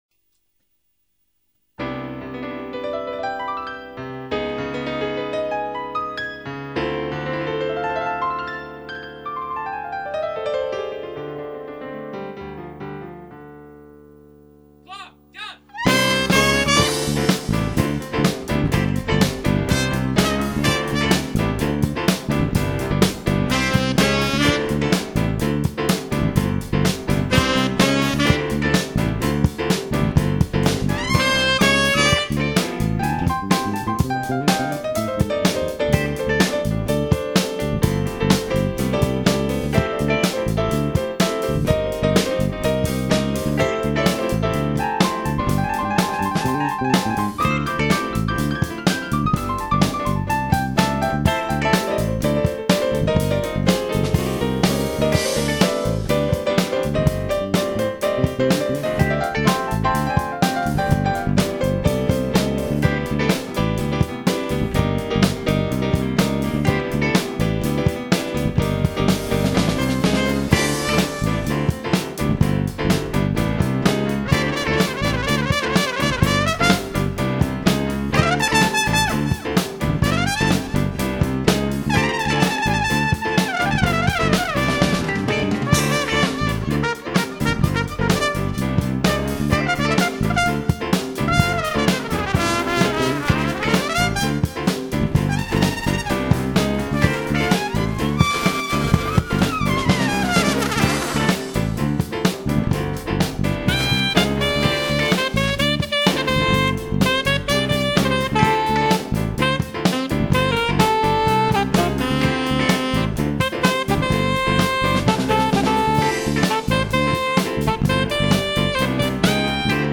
C'est une ancienne compo de lancien groupe avec mon batteur.
C'est assez funky. dommage que les cuivre sature un peu.
ca date de 1998, enregistré en studio. voili voilou
C'est très class avec le piano (bravo), j'aime bcp le riff de basse, j'aime bcp.
Tres sympa , j'aime bcp ton son , le solo de guitare est terrible
Mais y'a juste une chose , le solo de basse me semble un peu flou !
pis en fait si tu regarde bien, c une ligne de basse avec des gimique discoet funk. c'est pas révolutionnaire. pi ca manque de groove, et de fluidité
j'auime bien aussi, par contre je trouve qu'il te manque un peu d'attaque à ta basse, ajoute peut être un peu d'aigu ou de mediums.
sinon nickel, ca swing comme j aime